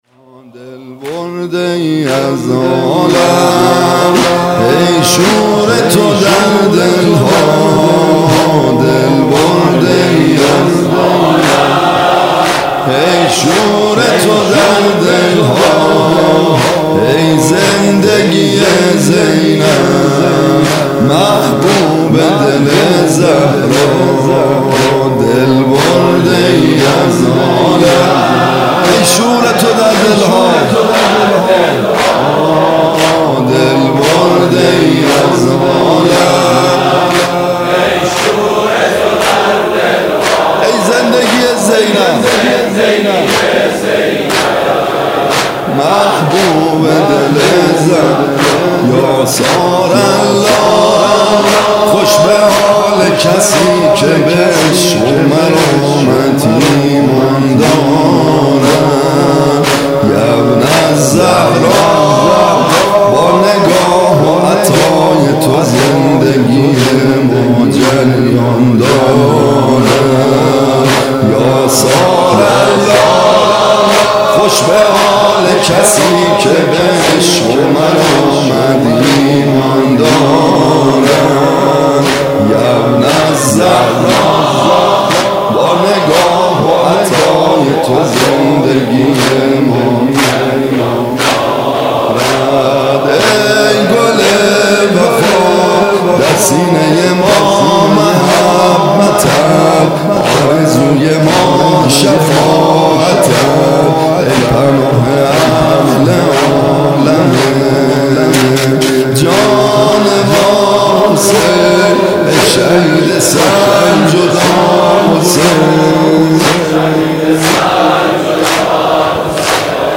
music-icon زمینه: دل برده‌ای از عالم، ای شور تو در دل‌ها